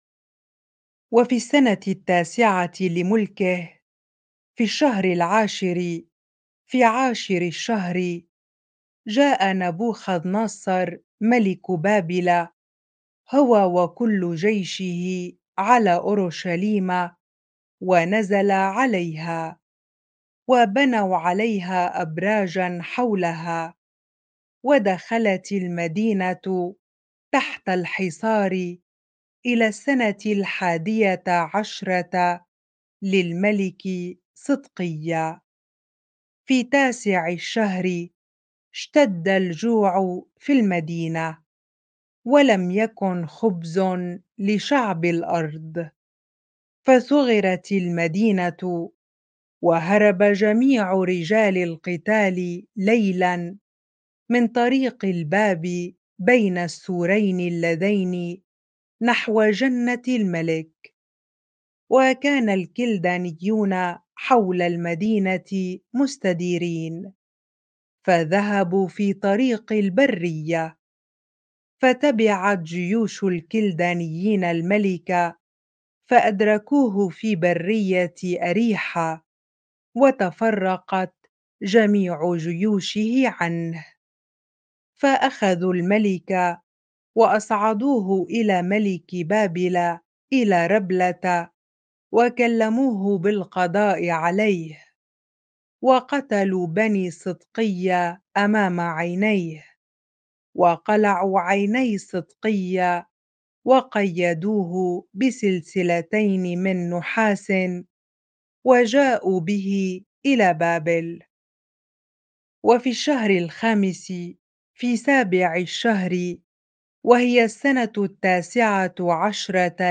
bible-reading-2 Kings 25 ar